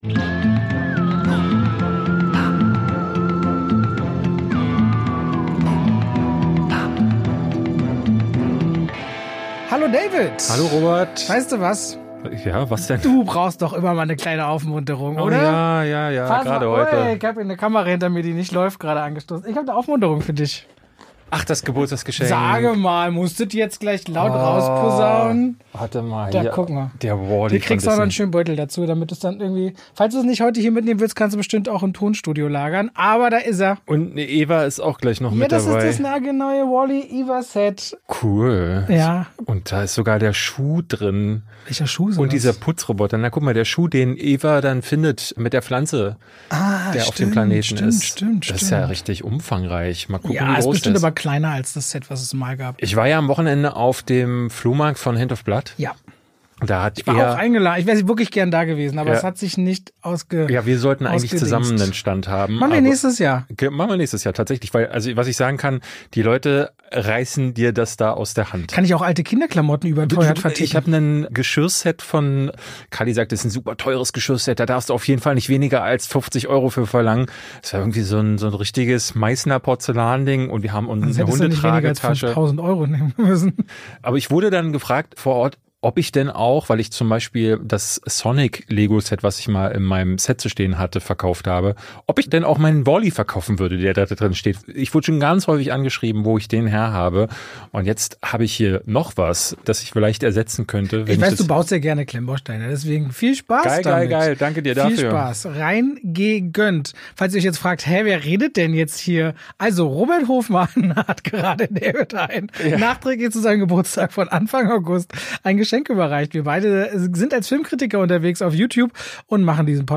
Diese Woche haben wir besonderen Besuch: Regisseur Dennis Gansel ist bei uns im Podcast. Wir sprechen mit ihm über seinen neuen Film Der Tiger, über die Relevanz von Kriegsdramen für eine junge Generation und darüber, wie sich Filmemachen im Zeitalter der Streamer verändert.